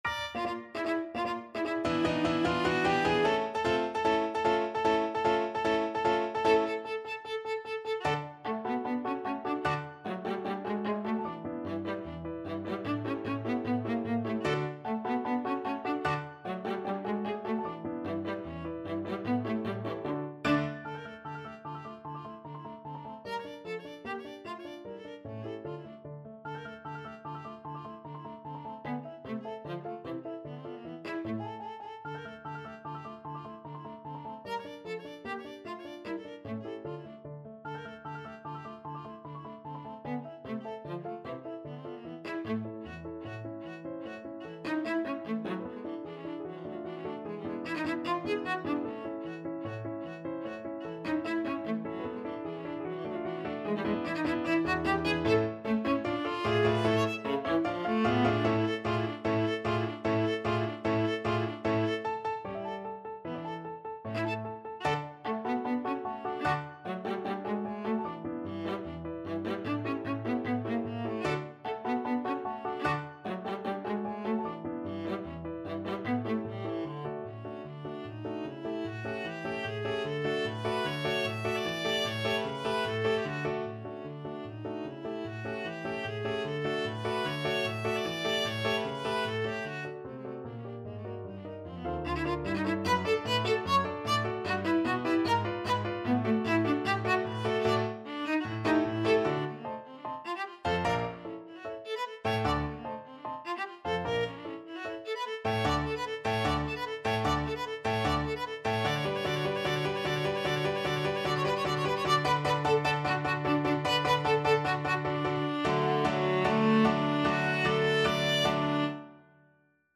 Viola version
2/4 (View more 2/4 Music)
Allegro vivacissimo ~ = 150 (View more music marked Allegro)
Classical (View more Classical Viola Music)